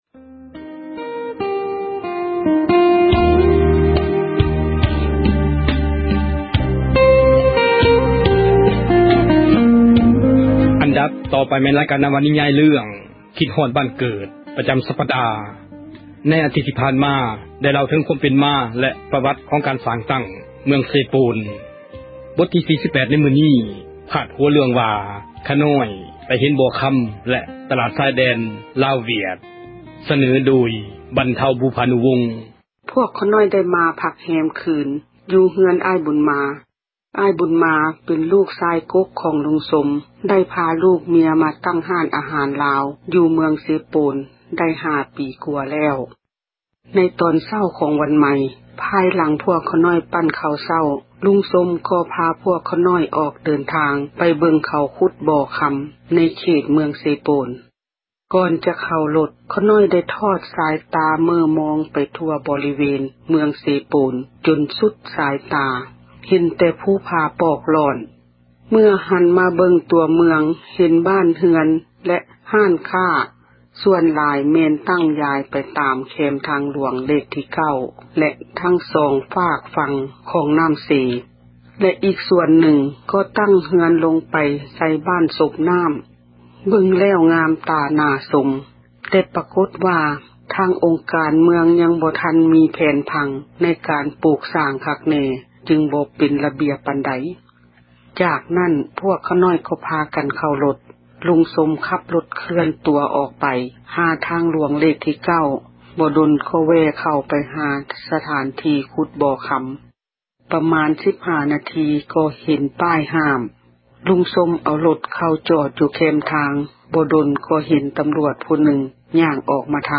ນິຍາຍ ເຣື້ອງ ຄິດຮອດບ້ານເກີດ ປະຈຳສັປດາ ບົດທີ 47.